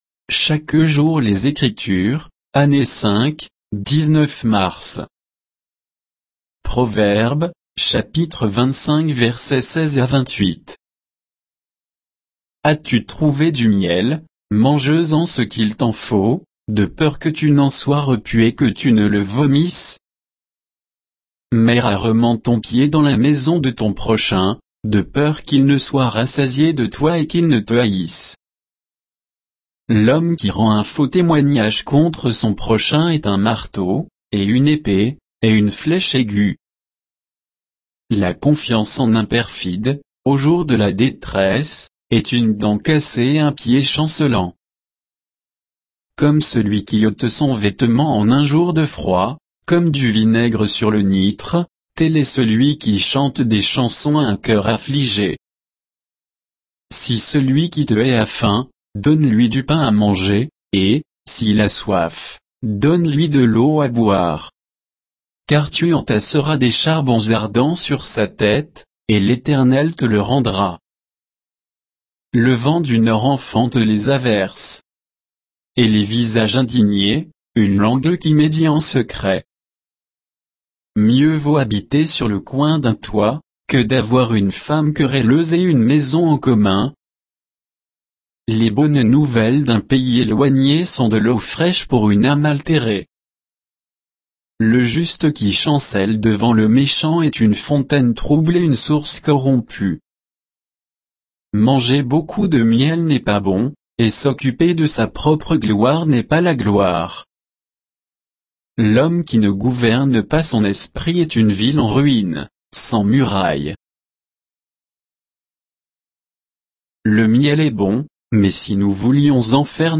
Méditation quoditienne de Chaque jour les Écritures sur Proverbes 25